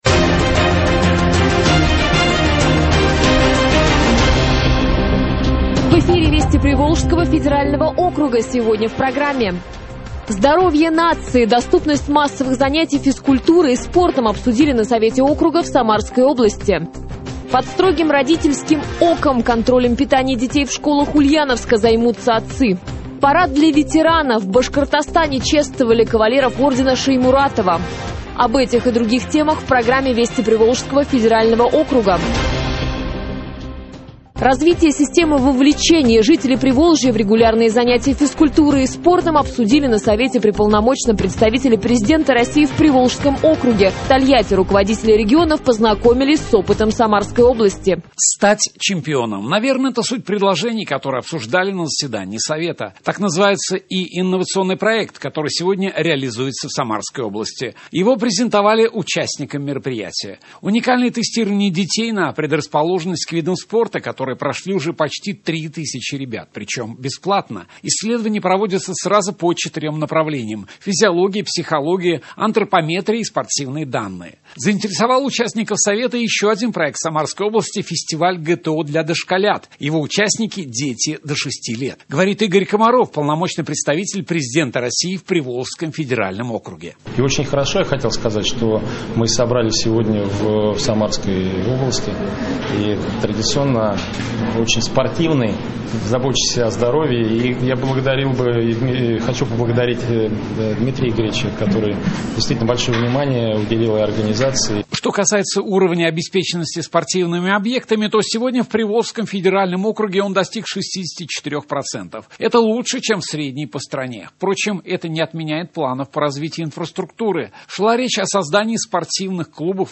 Теперь сборник «Вести ПФО» доступен в радиоформате, а значит, самые яркие события Приволжского федерального округа прозвучат и в нашем радиоэфире.